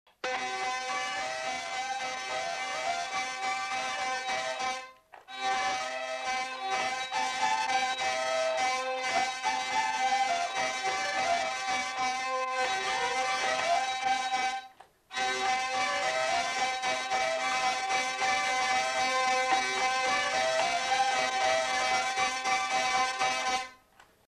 Lieu : Herré
Genre : morceau instrumental
Instrument de musique : vielle à roue
Danse : polka des bébés